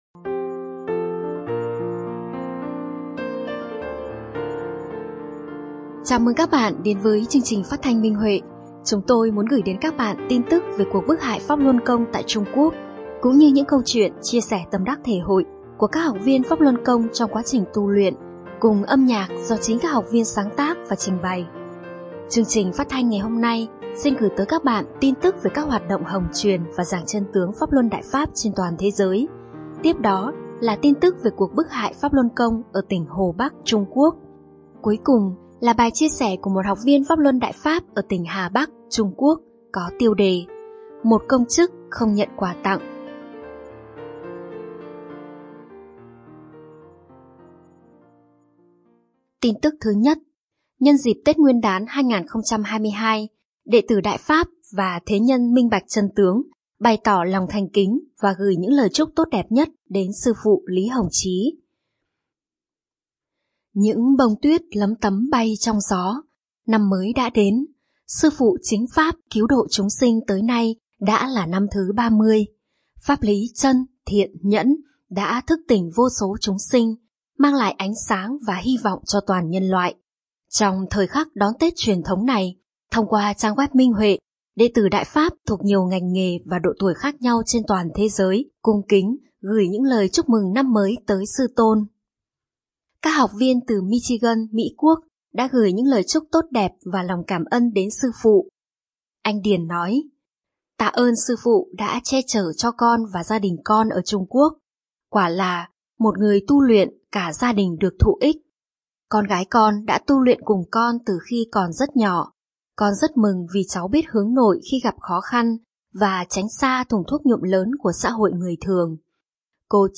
Chào mừng các bạn đến với chương trình phát thanh Minh Huệ. Chúng tôi muốn gửi đến các bạn tin tức về cuộc bức hại Pháp Luân Công tại Trung Quốc cũng như những câu chuyện chia sẻ tâm đắc thể hội của các học viên Pháp Luân Công trong quá trình tu luyện, cùng âm nhạc do chính các học viên sáng tác và trình bày.